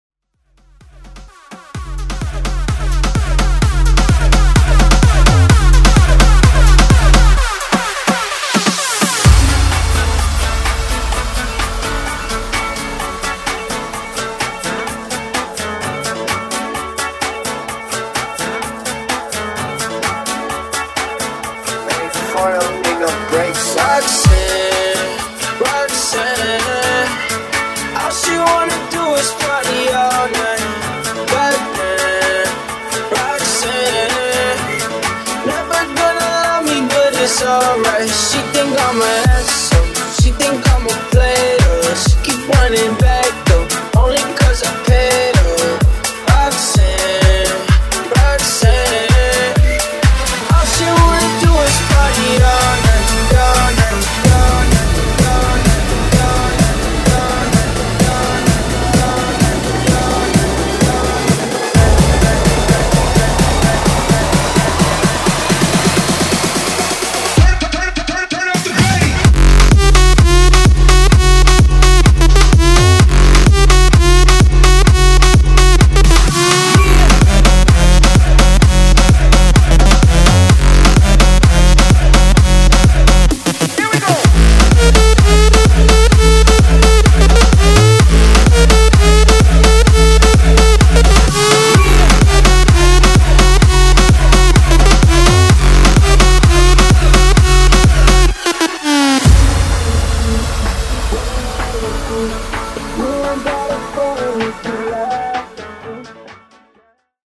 Genres: 90's , FUTURE HOUSE
Clean BPM: 128 Time